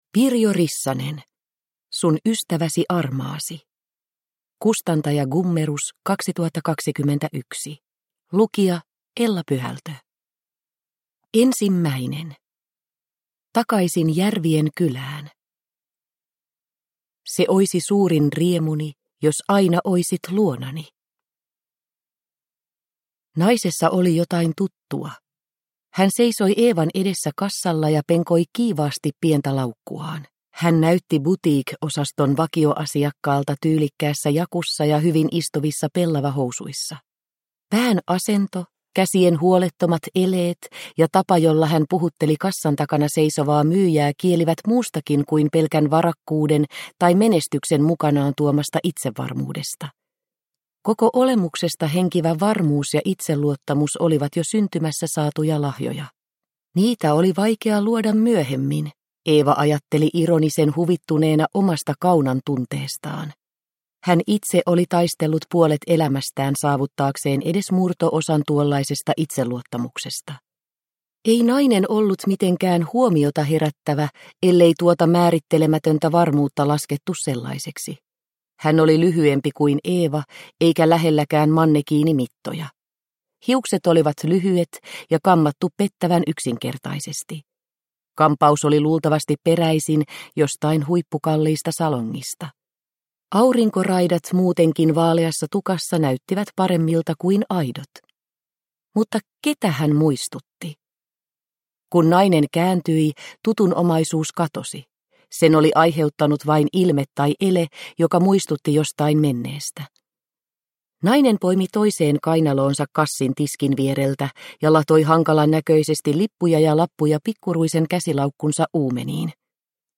Sun ystäväsi armaasi – Ljudbok – Laddas ner